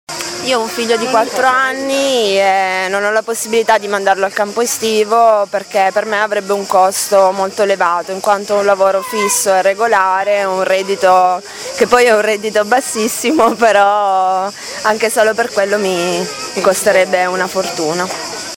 Abbiamo fatto un giro nei parchi di Bologna alla ricerca di mamme con pargoli e abbiamo chiesto ad alcune di loro un’opinione sui campi estivi.
Costano troppo come ci ha ricordato anche un’altra giovane mamma